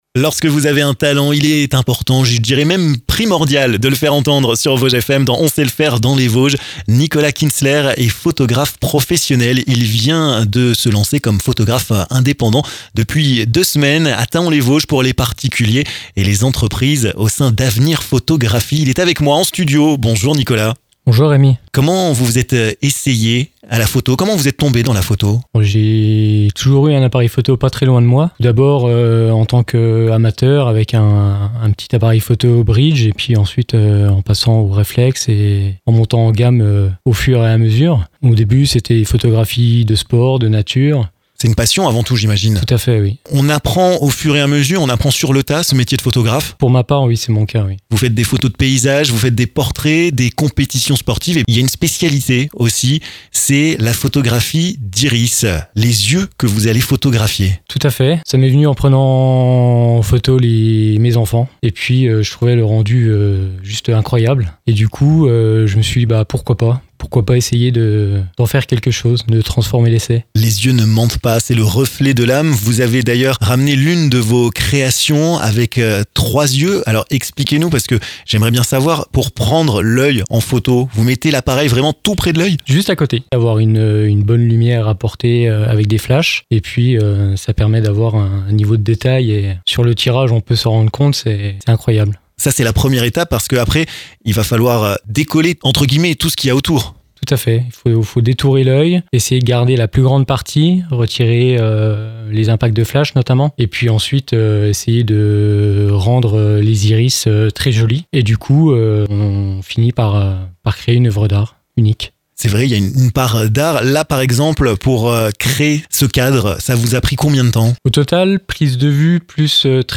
%%La rédaction de Vosges FM vous propose l'ensemble de ces reportages dans les Vosges%%
Un savoir-faire à découvrir dans cette interview!